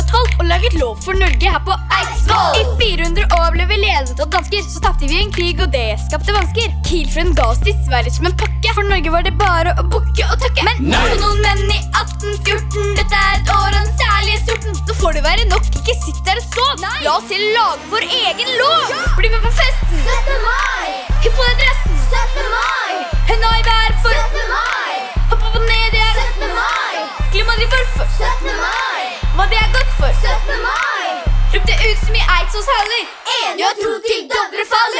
Жанр: Рэп и хип-хоп
# Hip-Hop